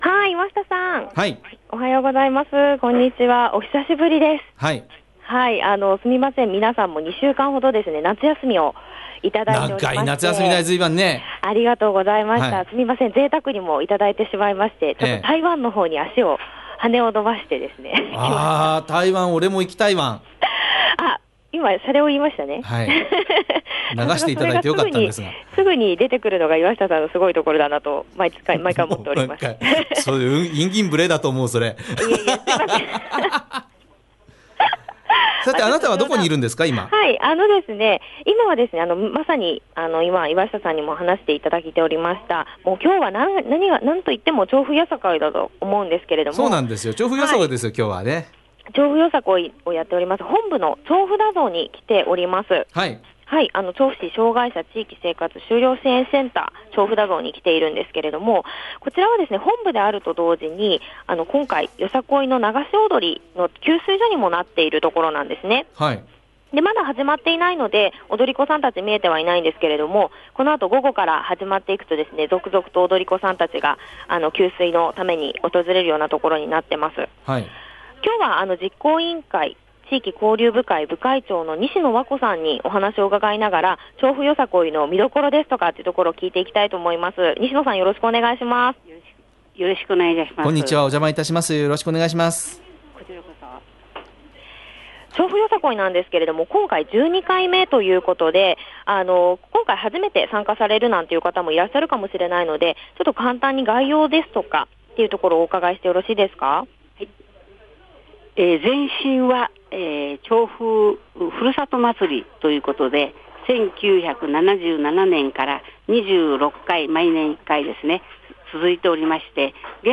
「ちょうふだぞう」でインタビューさせていただきました☆写真は準備中の様子。